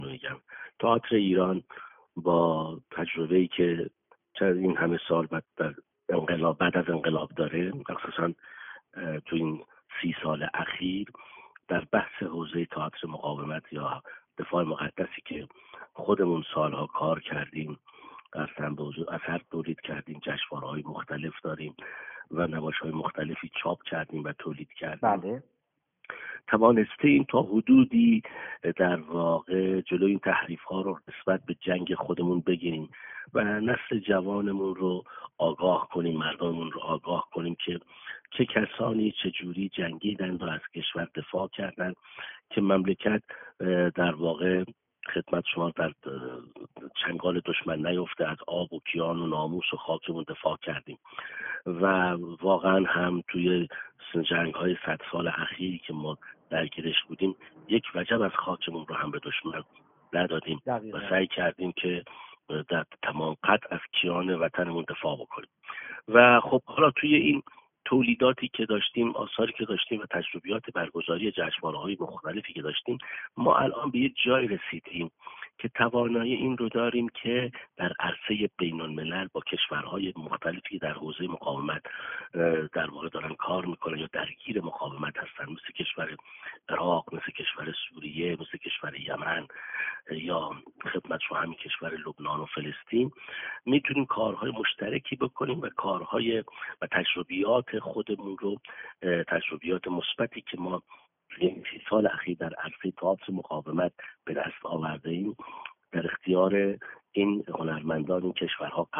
در گفت‌وگو با ایکنا: